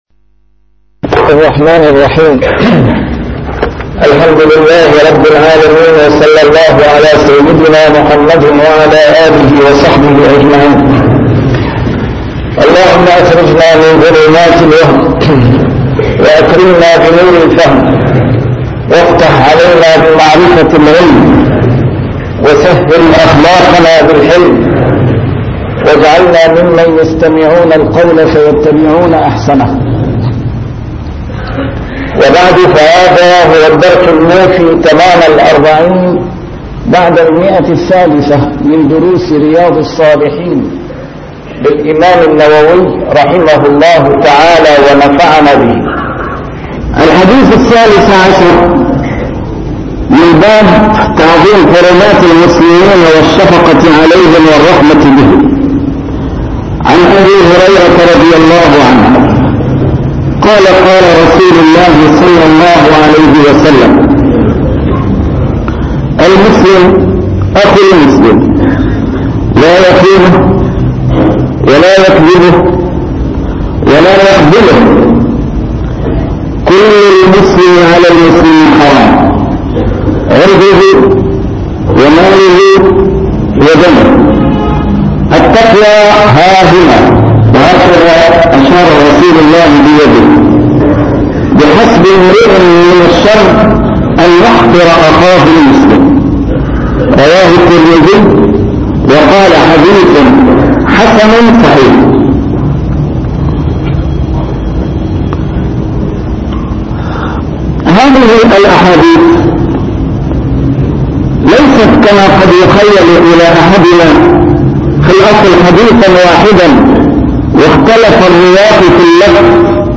A MARTYR SCHOLAR: IMAM MUHAMMAD SAEED RAMADAN AL-BOUTI - الدروس العلمية - شرح كتاب رياض الصالحين - 340- شرح رياض الصالحين: تعظيم حرمات المسلمين